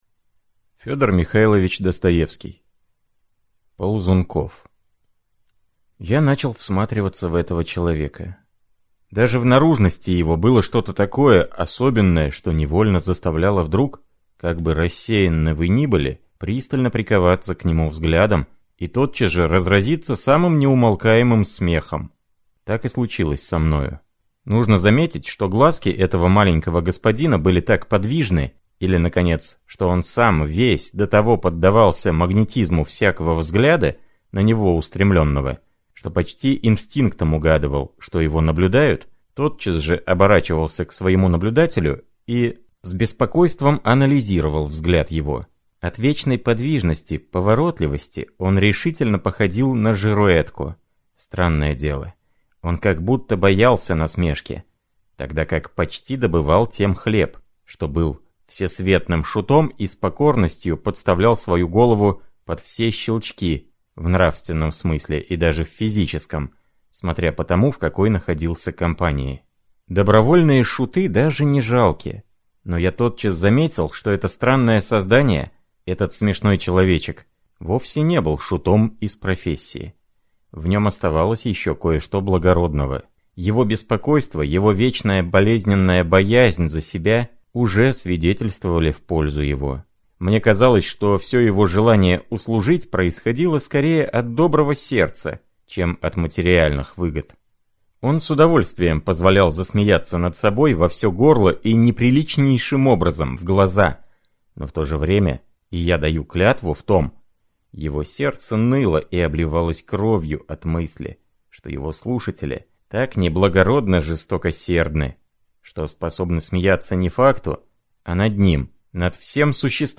Аудиокнига Ползунков | Библиотека аудиокниг